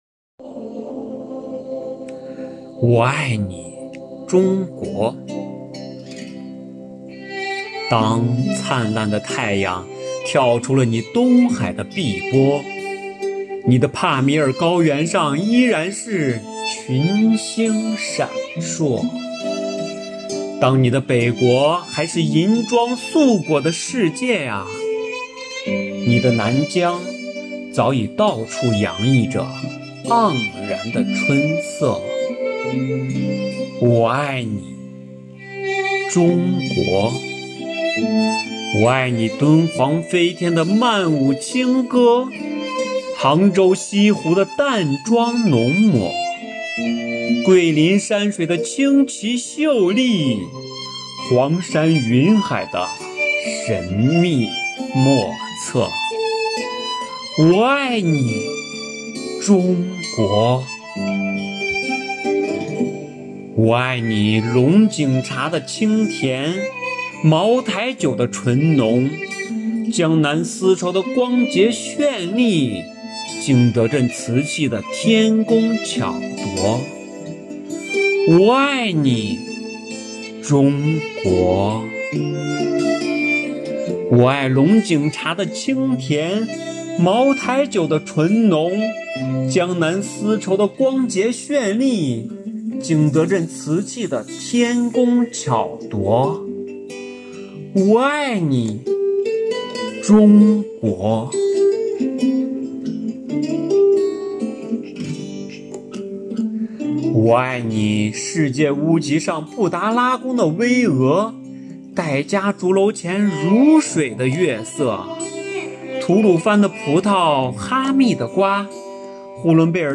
在五一劳动节、五四青年节来临之际，市纪委监委宣传部、机关党委组织青年干部，以“奋进新征程筑梦新时代”为主题，以朗诵为载体，用诗篇来明志，抒发对祖国的热爱、对梦想的执着、对青春的礼赞。